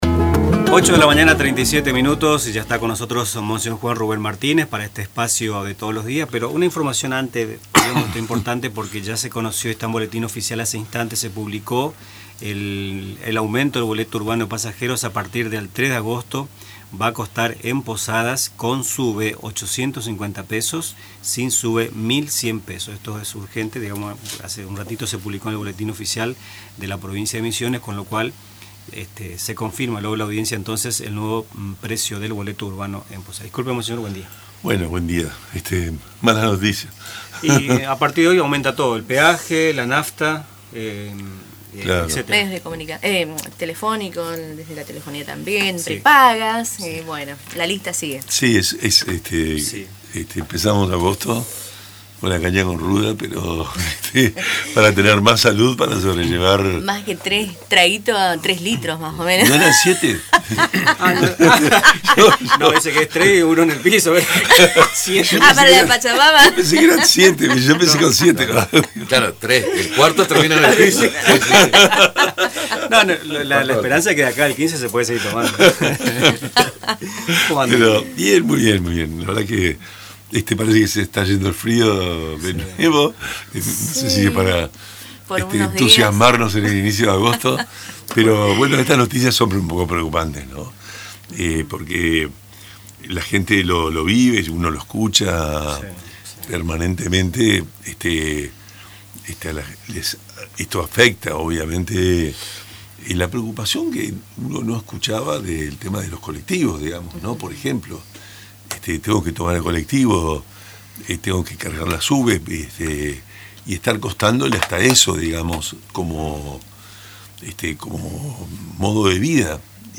En su visita habitual a los estudios de Radio Tupa Mbae, el obispo de la diócesis de Posadas, monseñor Juan Rubén Martínez, abordó diversos temas de interés.